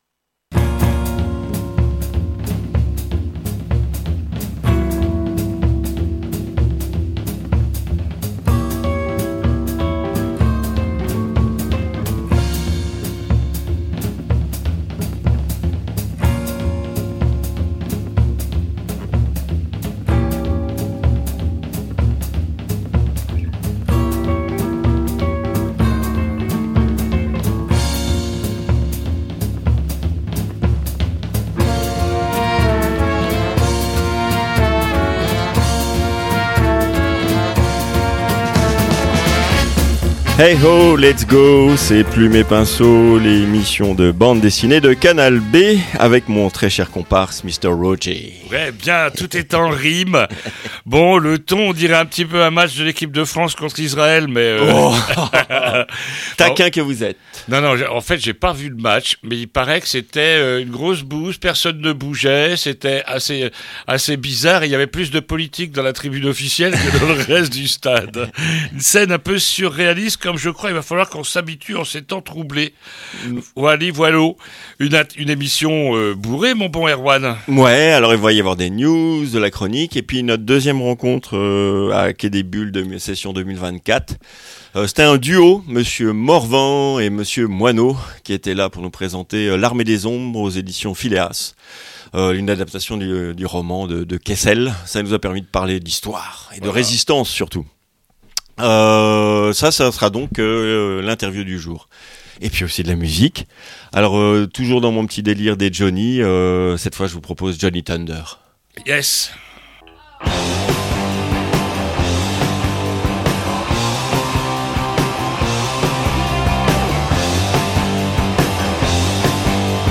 II - INTERVIEW L'Armée des ombres ? ils ne sont pas morts pour rien !